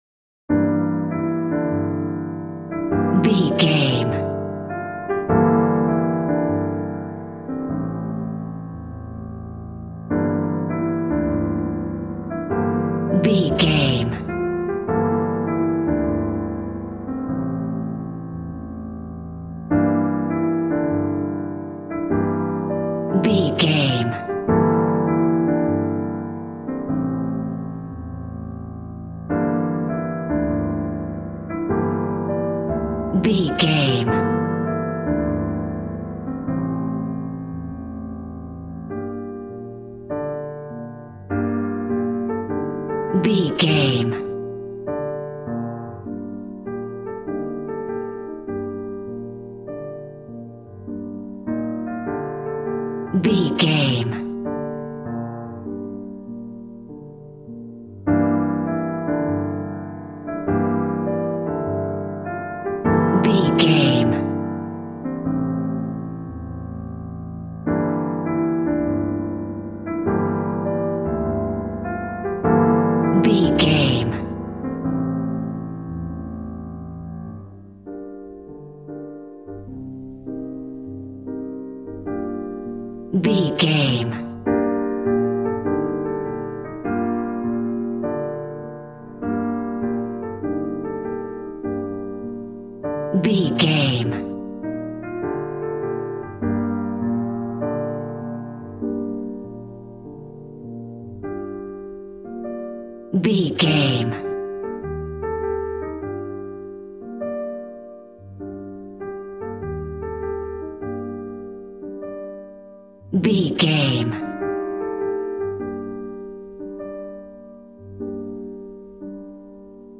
Smooth jazz piano mixed with jazz bass and cool jazz drums.,
Aeolian/Minor
smooth
drums